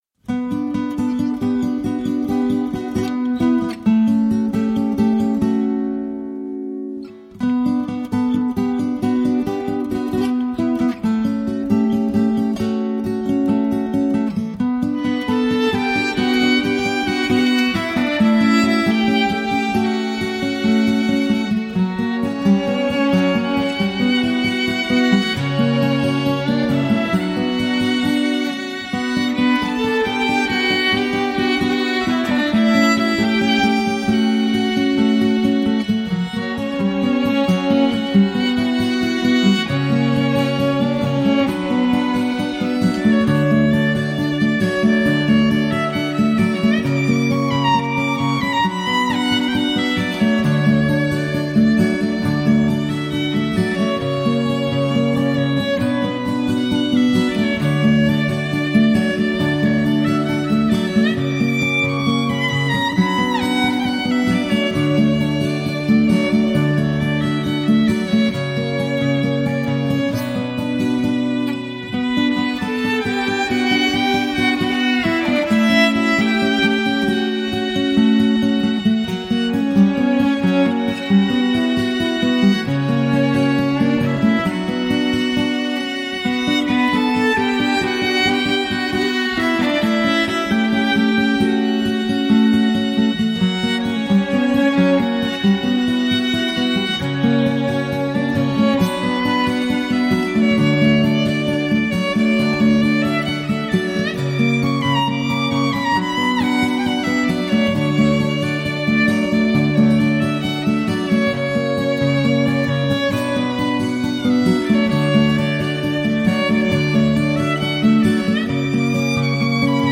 Žánr: World music/Ethno/Folk
housle
kytara